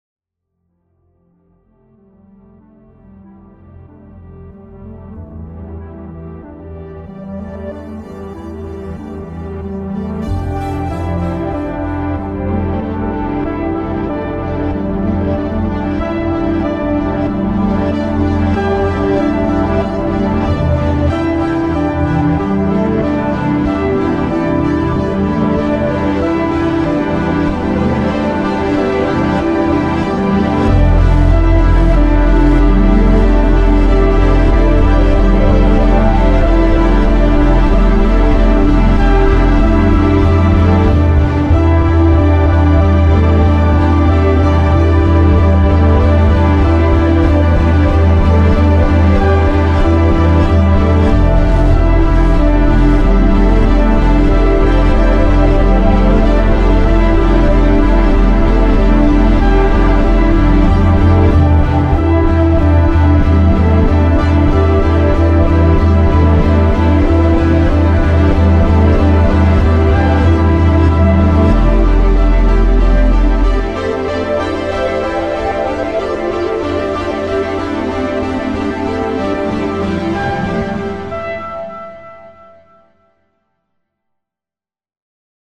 复古低保真音源 Teletone Audio Le Gibet KONTAKT-音频fun
Le Gibet 的声音是由模拟合成器、键盘和弦乐器的录音经过失真和饱和处理而制成的，可以模拟出磁带老化的温暖和浪漫。
Le Gibet 包含了超过 75 个单独的乐器和 5 个多重乐器，涵盖了各种风格和类型的键盘、垫片、贝斯、主音和合成器。